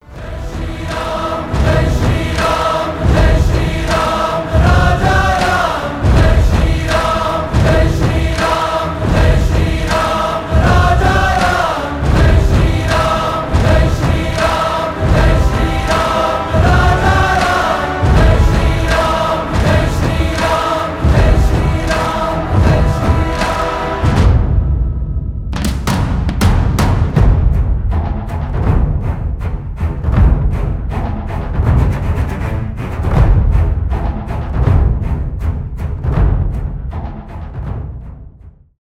инструментальные